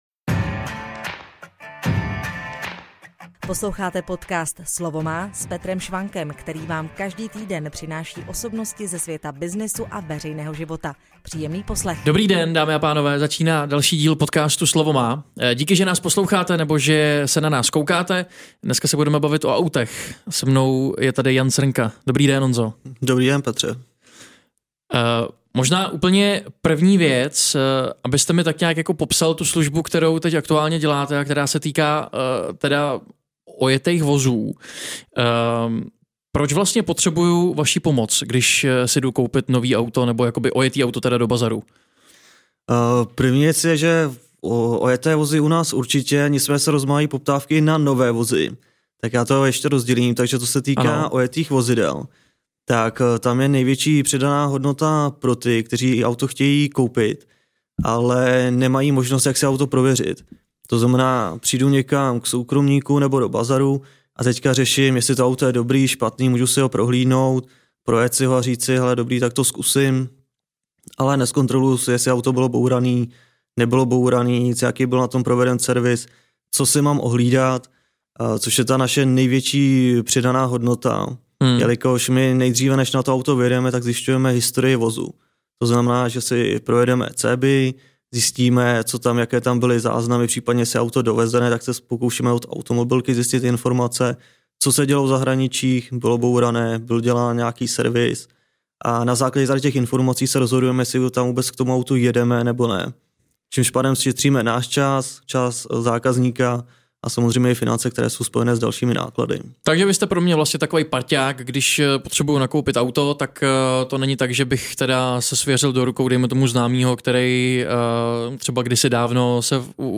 Jak vlastně celá služba funguje a proč o ní zatím mnoho lidí neví? Poslechněte si v rozhovoru.